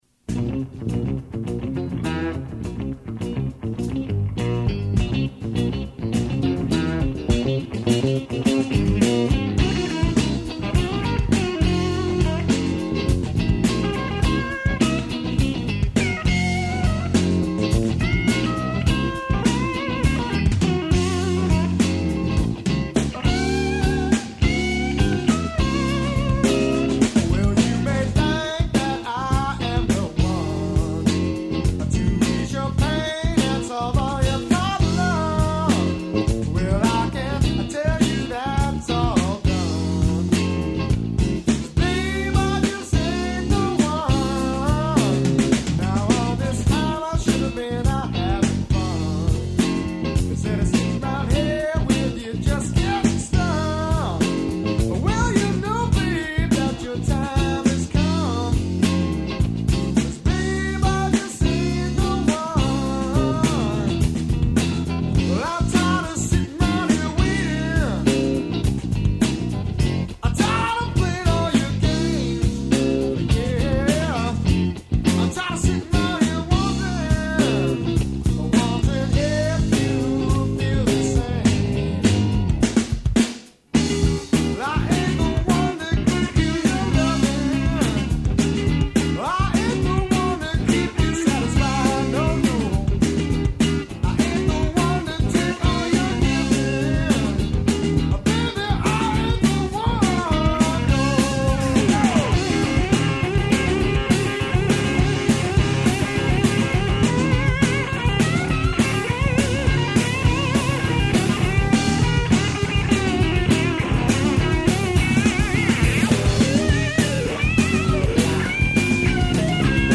Demo cassette (unavailable)
Tobias (pre-Gibson, circa 1986) Basic 5-String Bass